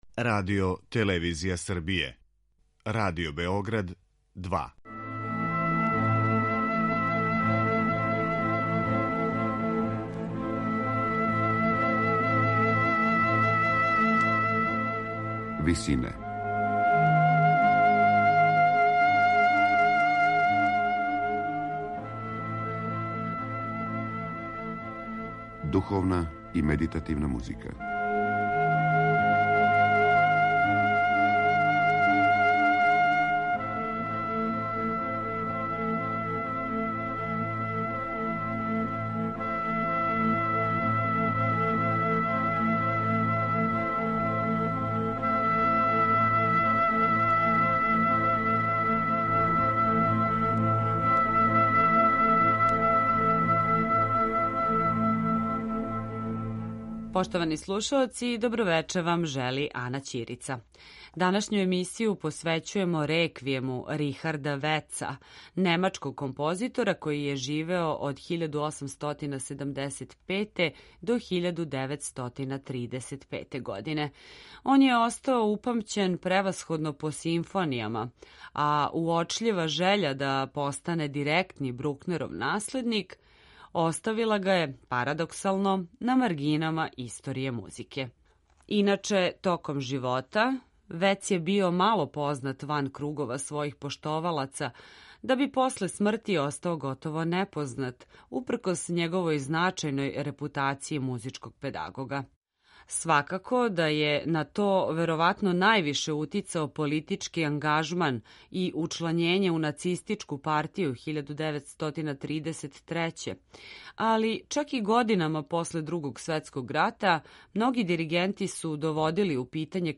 Реквијем